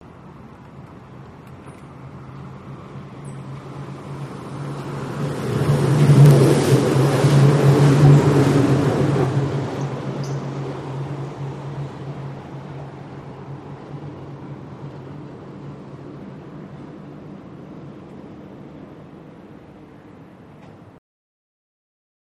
Monorail, Disneyland, Close Up, Mini Squeaks In, Out